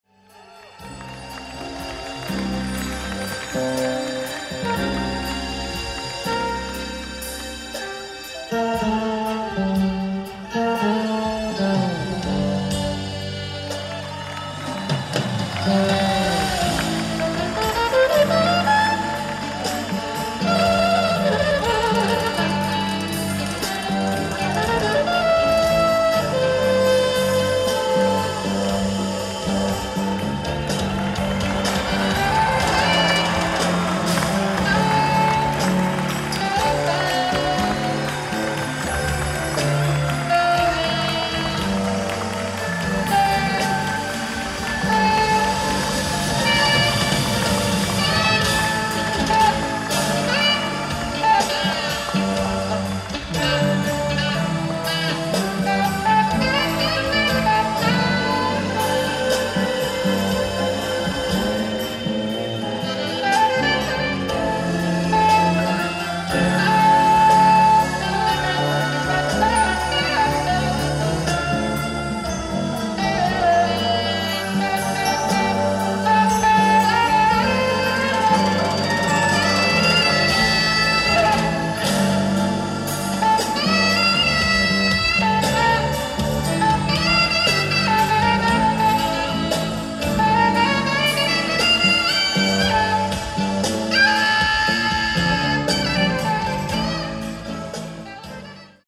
Live At Center Coliseum, Seattle, WA